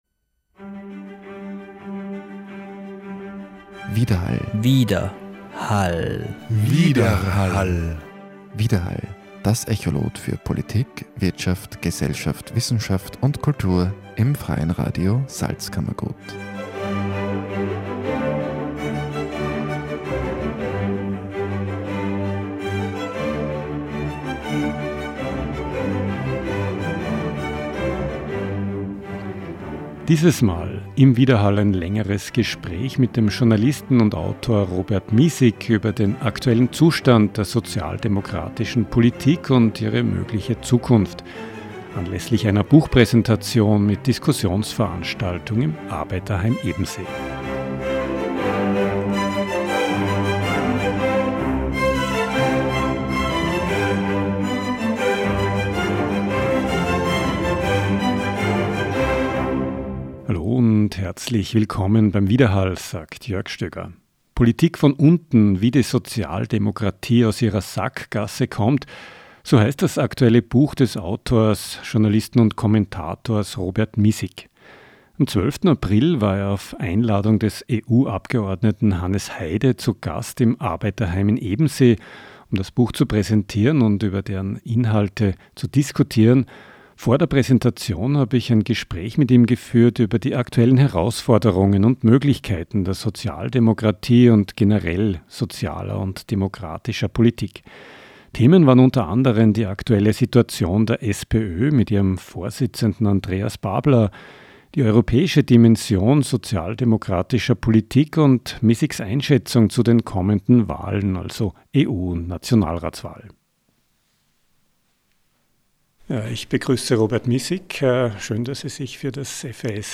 Am 12. April war er auf Einladung des EU-Abgeordneten Hannes Heide zu Gast im Arbeiterheim in Ebensee. Im Interview vor der Veranstaltung spricht über die aktuellen Herausforderungen und Möglichkeiten der Sozialdemokratie in Österreich und der Europäischen Union.